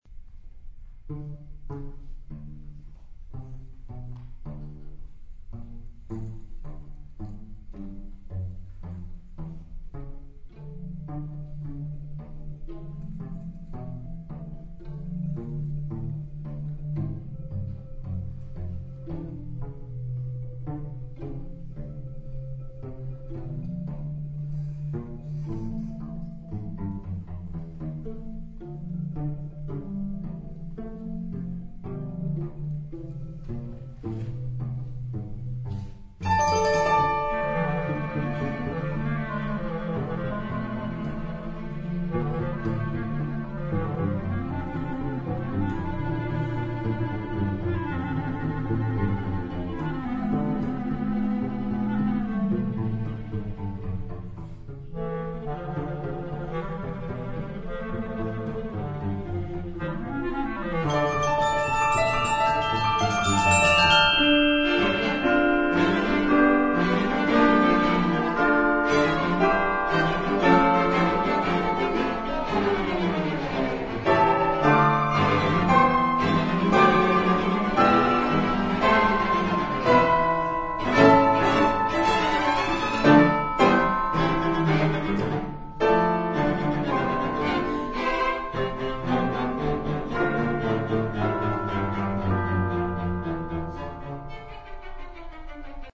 flute, clarinet, two percussion, piano, string quartet